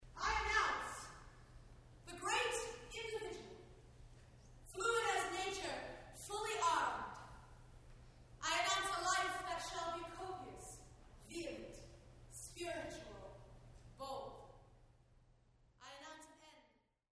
Live Recordings from "One-ery...Two-ery..."